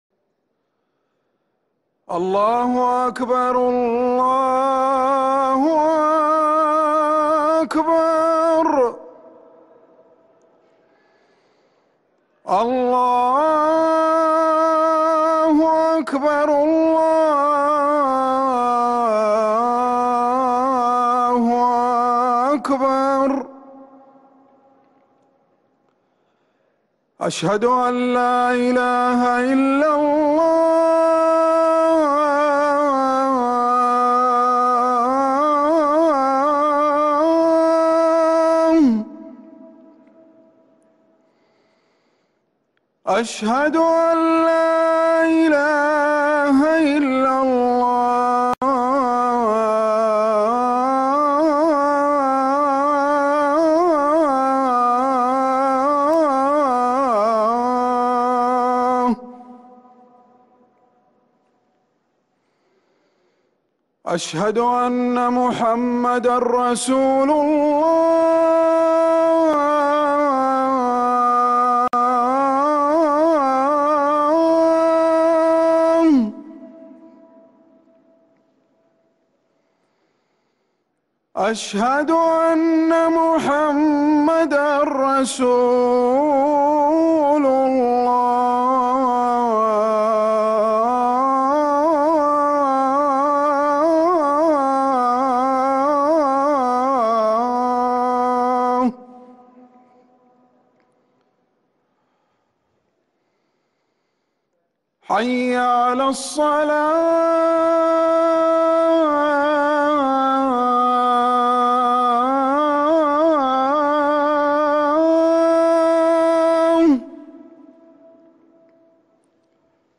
روائع الأذان